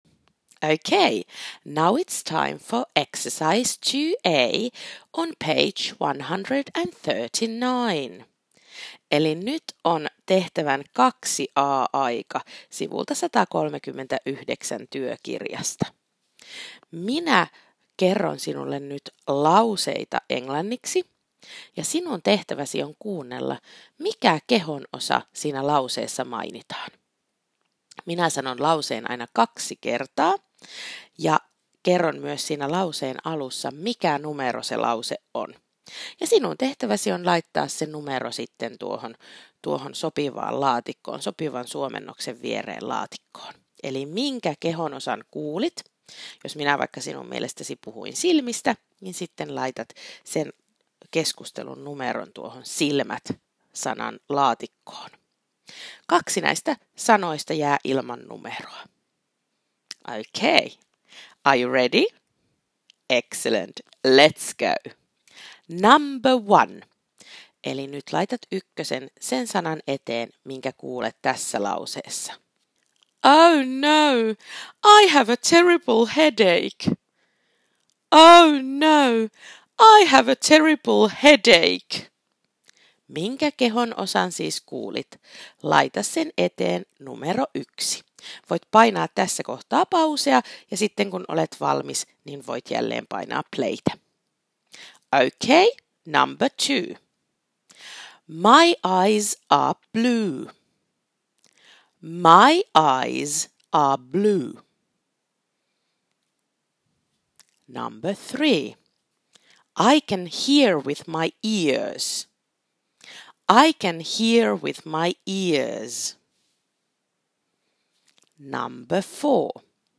* Kuuntele, mistä kehonosasta opettaja puhuu. Merkitse numero. Kaksi sanaa jää ilman numeroa.